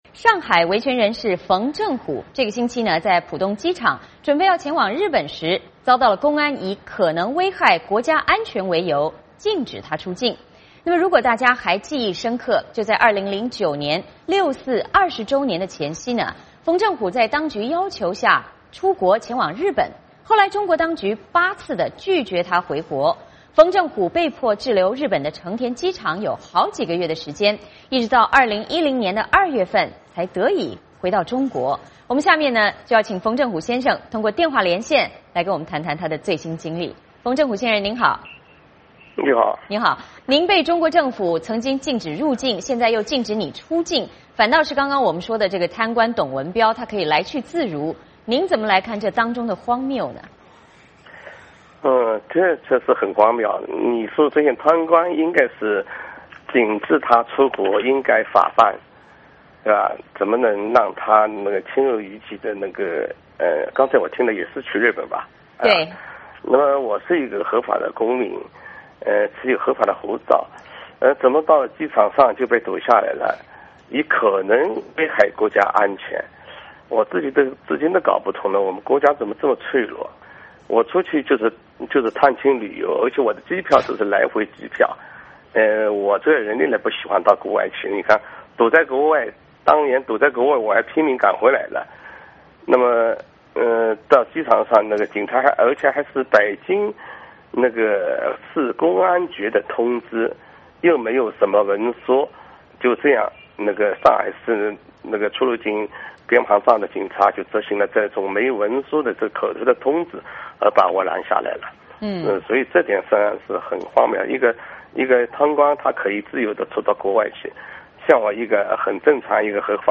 我们请冯正虎先生通过电话连线，来谈谈他的最新经历。